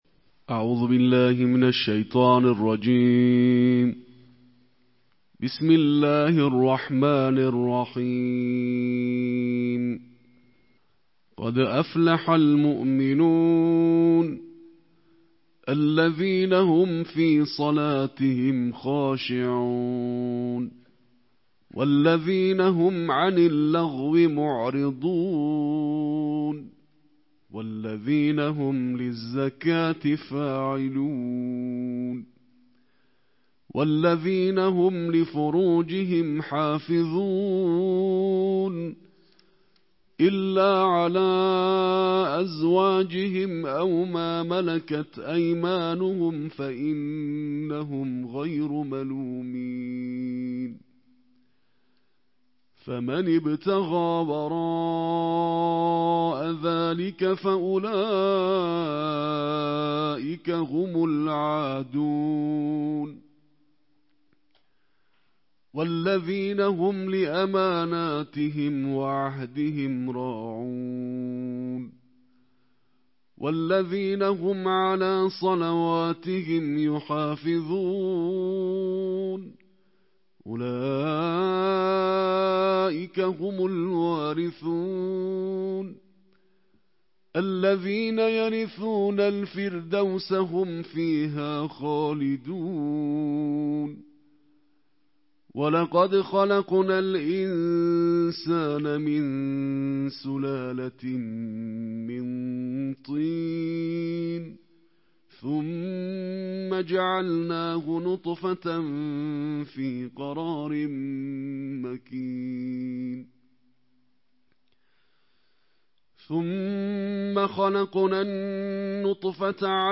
نړیوال قارِيان ،د قرآن کریم د اتلسمې(۱۸) سپارې یا جزوې د ترتیل قرائت